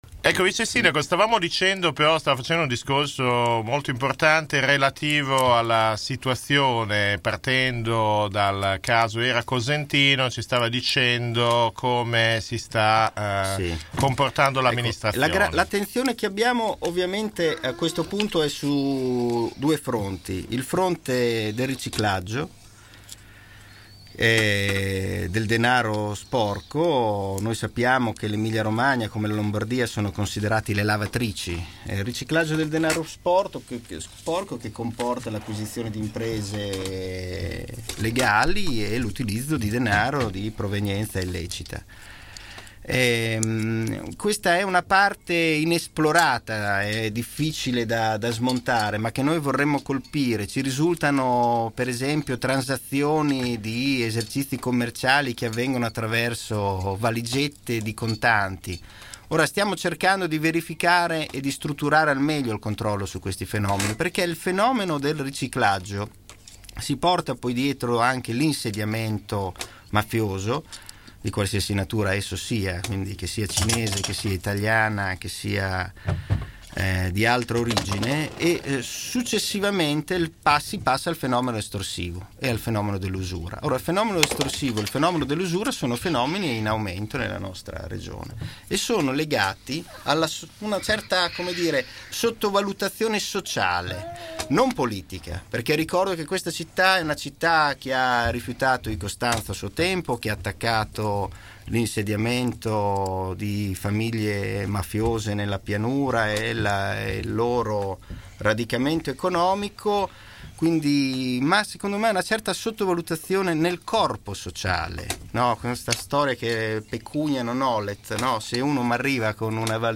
Ha il tono della polemica  il commento con cui il Questore Luigi Merolla, il Prefetto Angelo Tranfaglia e i vertici delle forze dell’ordine bolognesi (i comandanti Piero Burla per la Gdf e Antonio Massaro per i Carabinieri) hanno accolto l’allarme mafia e riciclaggio in città lanciato ieri dai nostri microfoni dal Vicesindaco Claudio Merighi, che aveva fatto riferimento a infiltrazioni mafiose in città attraverso prestanome pronti ad acquistare esercizi commerciali a prezzi fuori mercato e “pronto cassa” in contanti: (ascolta)